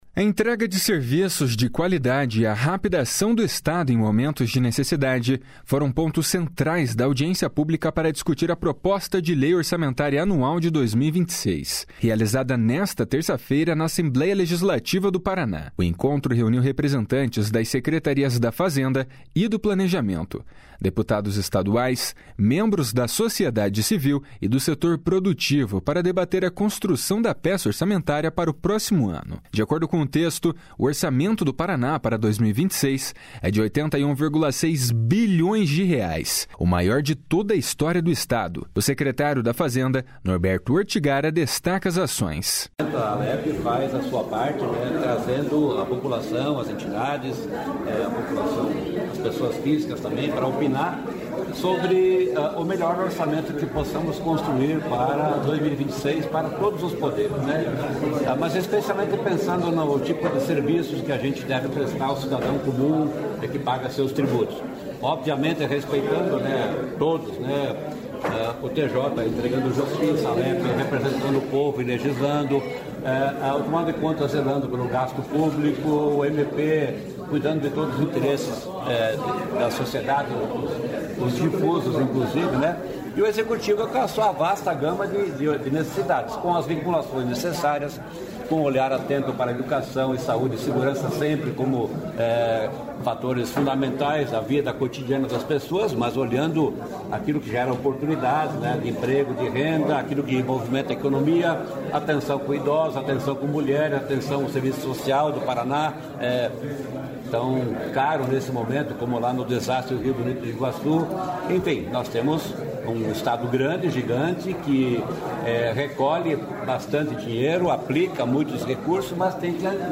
De acordo com o texto, o orçamento do Paraná para 2026 é de R$ 81,6 bilhões, o maior de toda a história do Estado. O secretário da Fazenda, Norberto Ortigara, destaca as ações. // SONORA NORBERTO ORTIGARA //